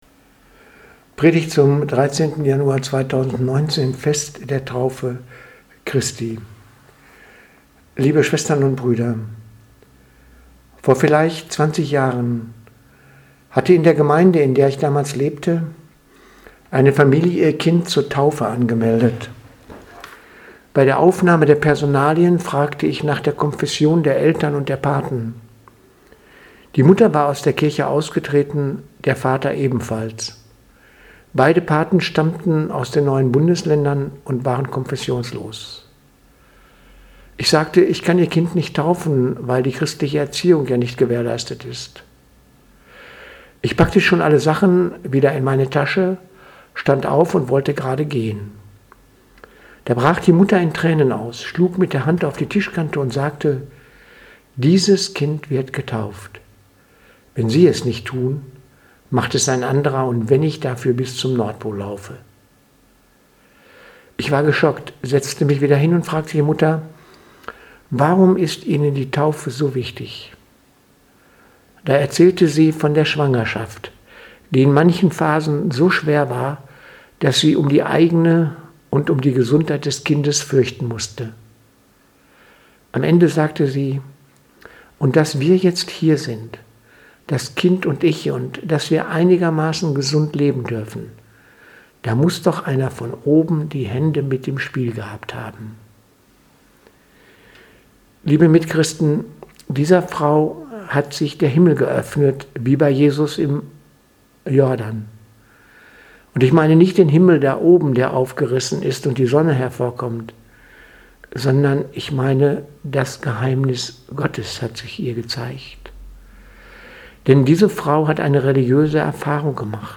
Predigt vom 13.1.2019 – Taufe